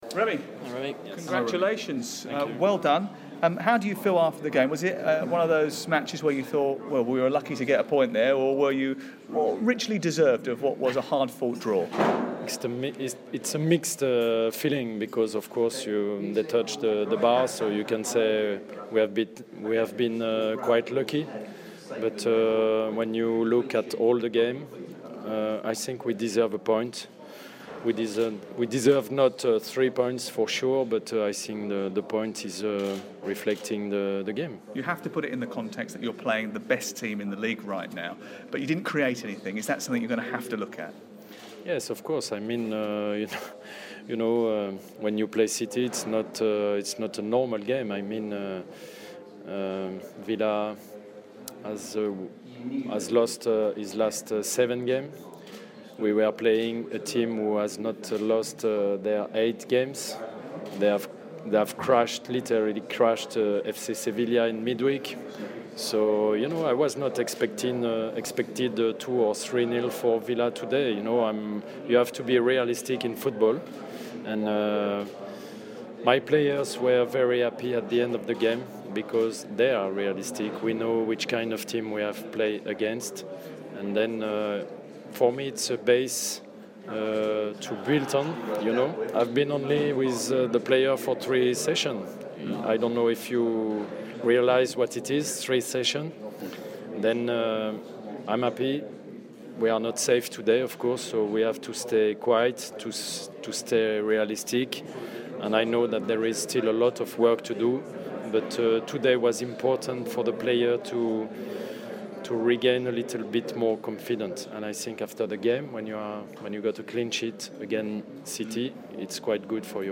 Aston Villa manger Remi Garde speaks to BBC WM following his side's 0-0 draw with Manchester City